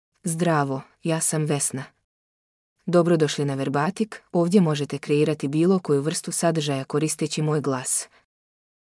Vesna — Female Bosnian (Bosnia and Herzegovina) AI Voice | TTS, Voice Cloning & Video | Verbatik AI
Vesna is a female AI voice for Bosnian (Bosnia and Herzegovina).
Voice sample
Female
Vesna delivers clear pronunciation with authentic Bosnia and Herzegovina Bosnian intonation, making your content sound professionally produced.